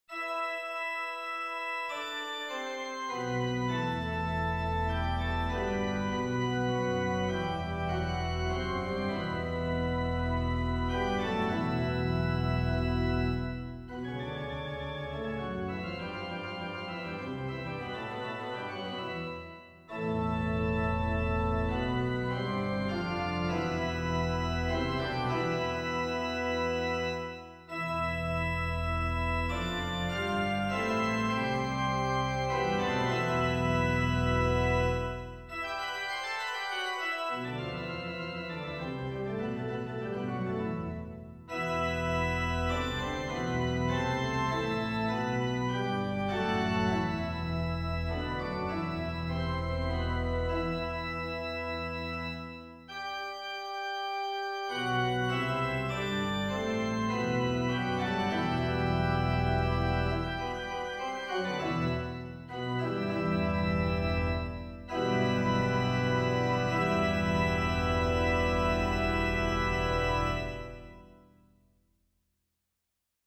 Soliloquy for Organ No. 4